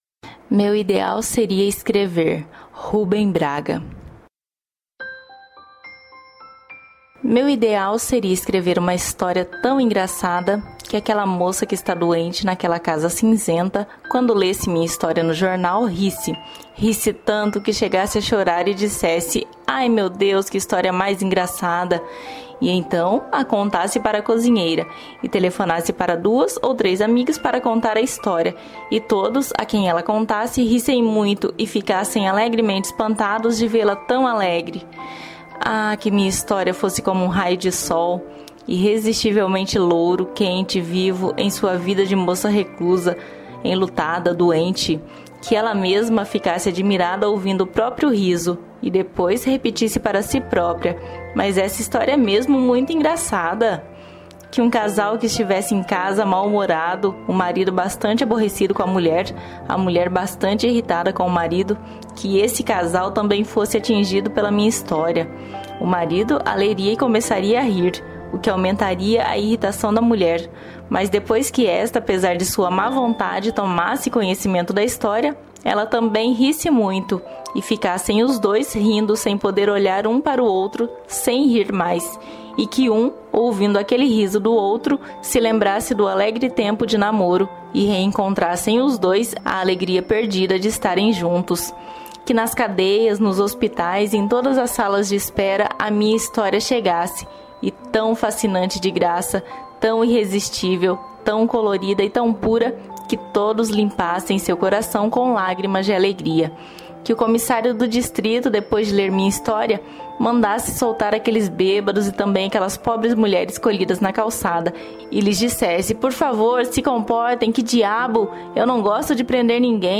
Poemas recitados de diferentes estilos e, de autores consagrados.
A melodia que acompanha o podcast é um instrumental da música; Sonda-me, usa-me da Aline Barros.